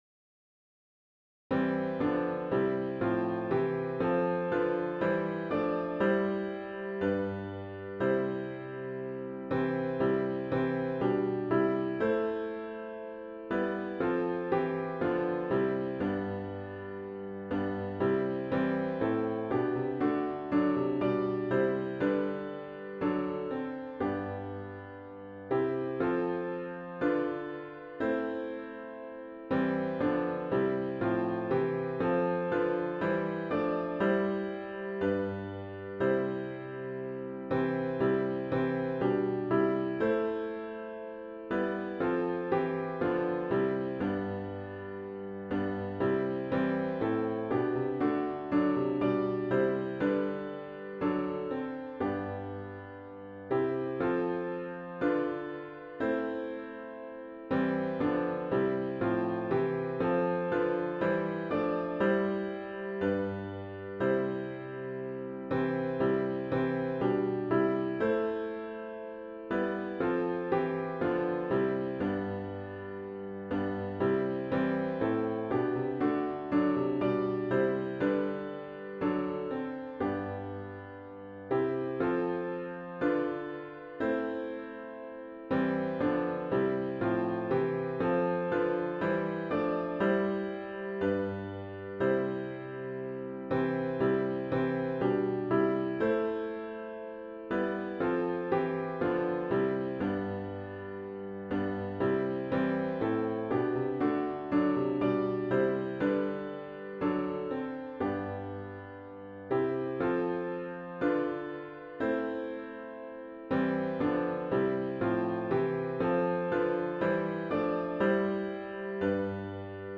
*OPENING HYMN “Rejoice, the Lord Is King!”